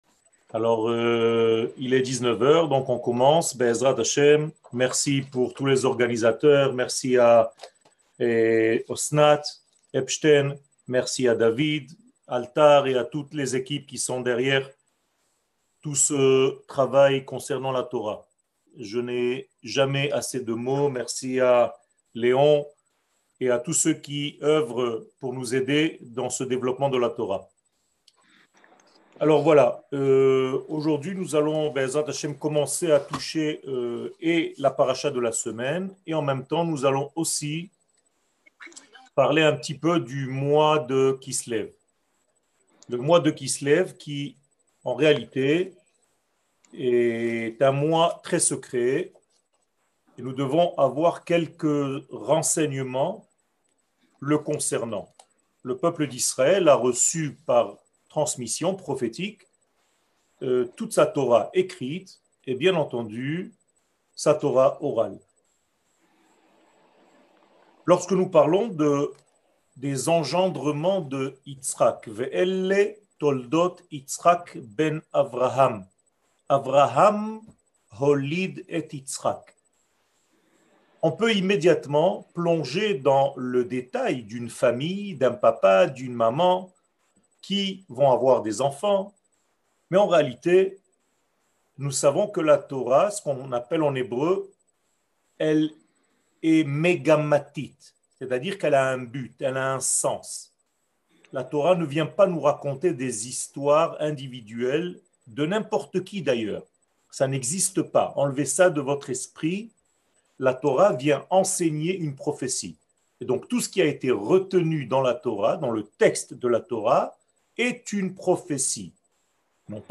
Cours paracha de la semaine Toledot